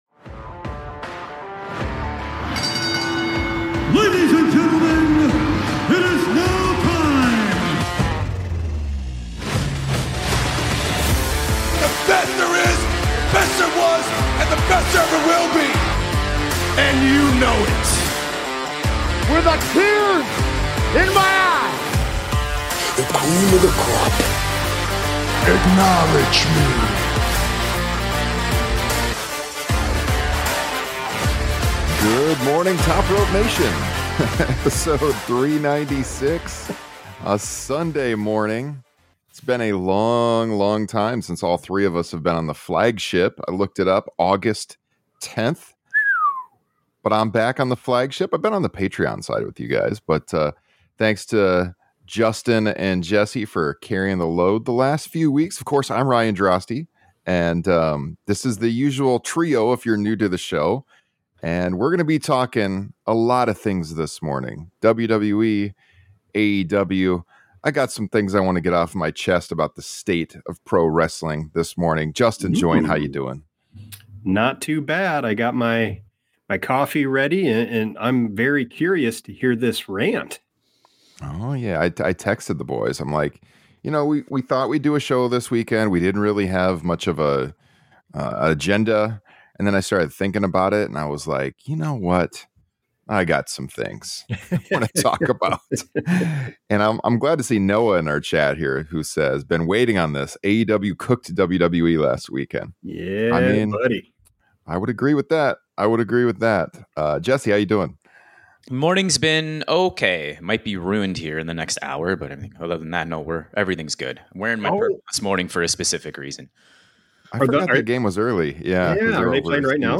Aew Interview Podcasts
1 Exclusive Interview: MVP BLASTS WWE’s Corporate Greed, Praises AEW’s Grassroots 52:50 Play Pause 8d ago 52:50 Play Pause Play later Play later Lists Like Liked 52:50 Legend of the ring MVP joins TMZ’s Inside The Ring for an explosive conversation. Fresh off AEW All Out, he breaks down the Hurt Syndicate’s setback and makes it clear the battle isn’t over.